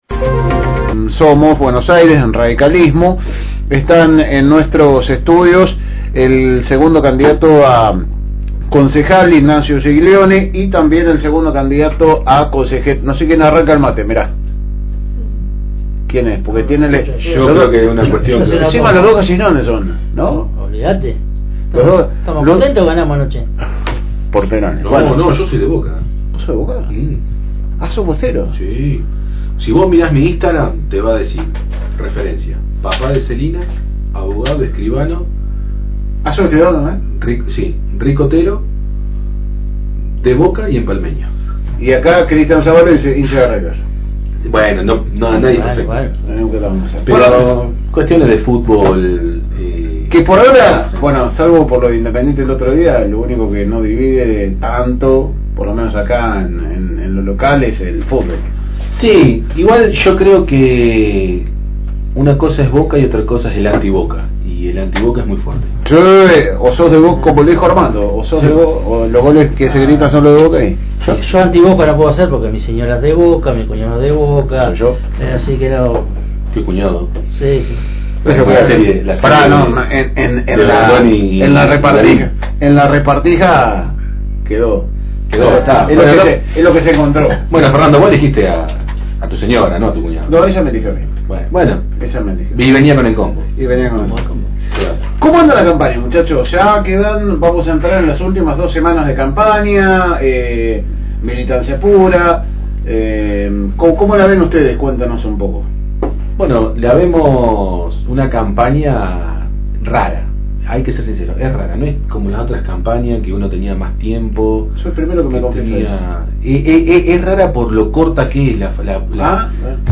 Espacio electoral.